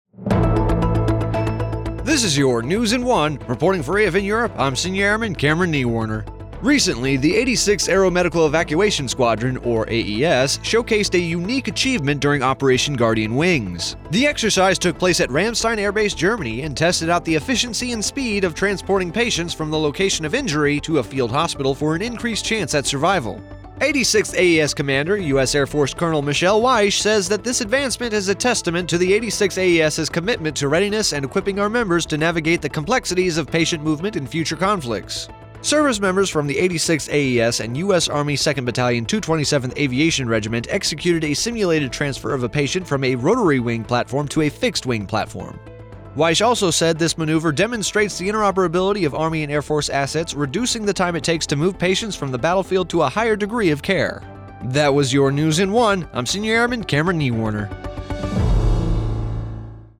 AES227 Aviation Regiment86th Aeromedical Evacuation SquadronNews in One